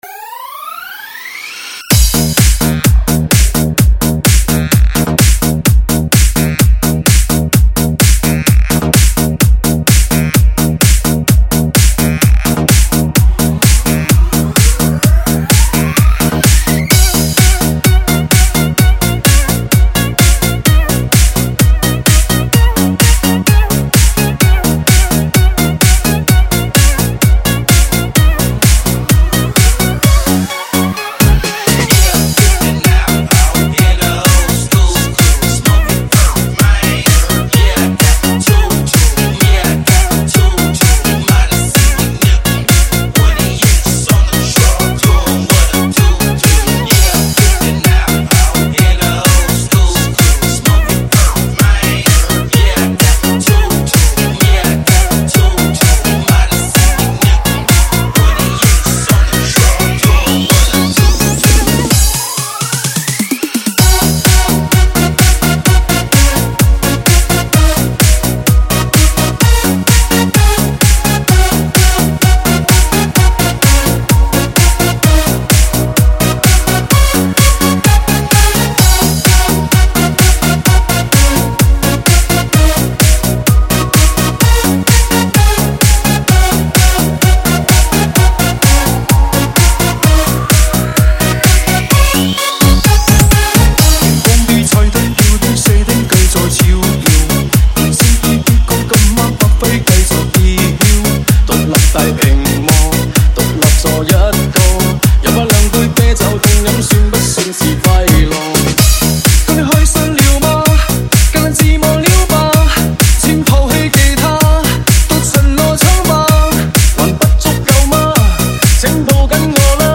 5天前 DJ音乐工程 · 推荐资源 · 音乐工程 2 推广